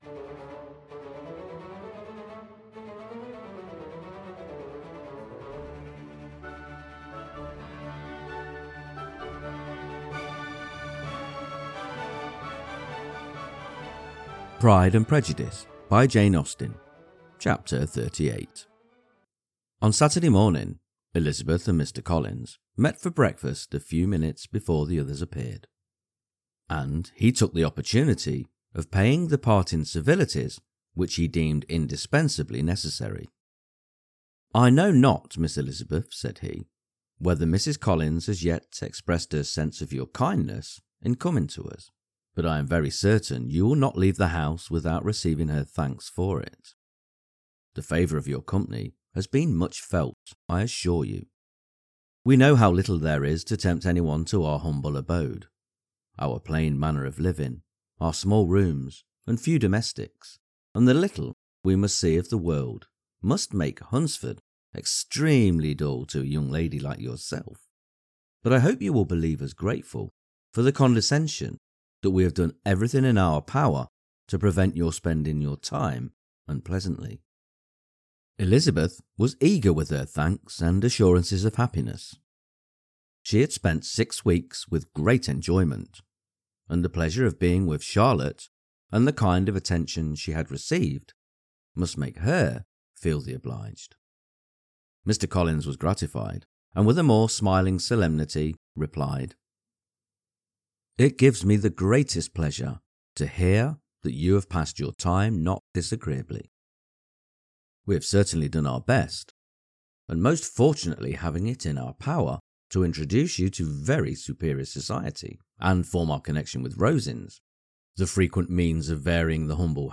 Pride and Prejudice – Jane Austen Chapter 38 Narrated